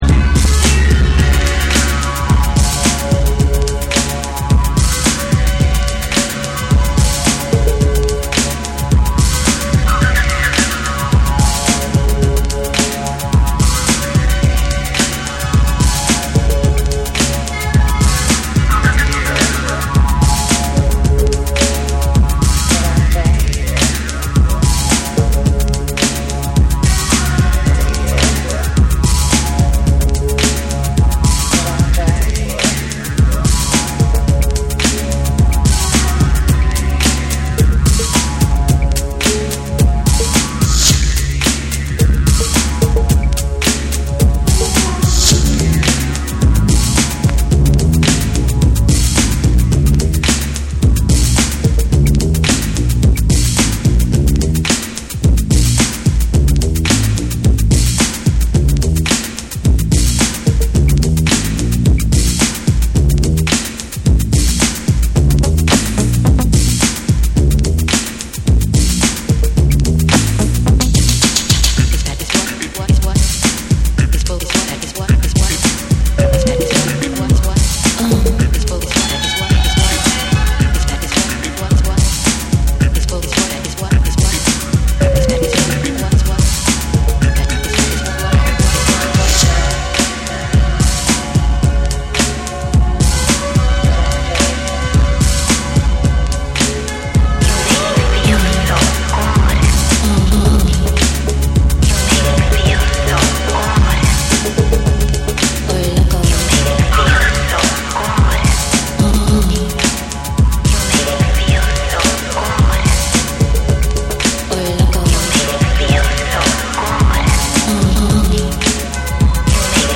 テクノとブレイクビーツが交差する躍動感のあるビートが、心地よいアンビエント空間に溶け込むエレクトリックでダビーな大名曲！